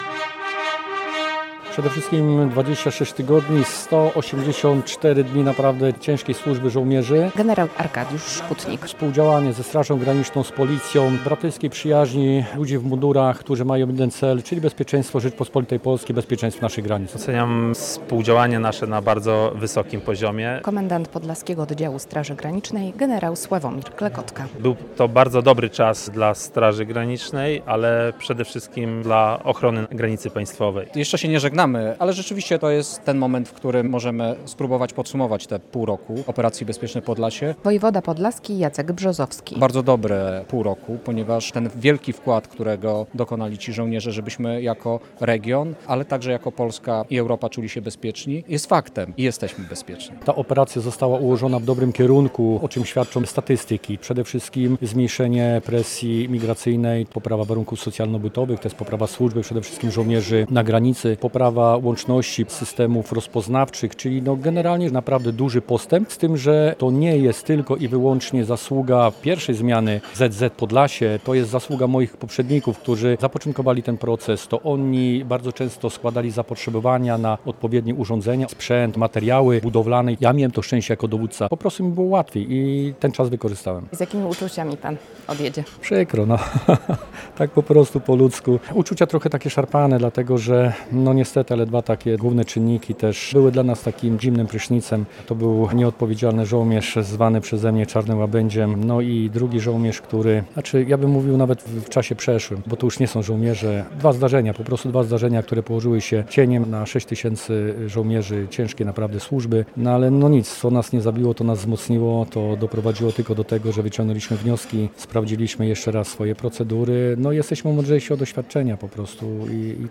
W sobotnie (25.01) popołudnie podczas koncertu wojskowej orkiestry żołnierze 18. Dywizji Zmechanizowanej dziękowali wszystkim, z którymi współpracowali od sierpnia.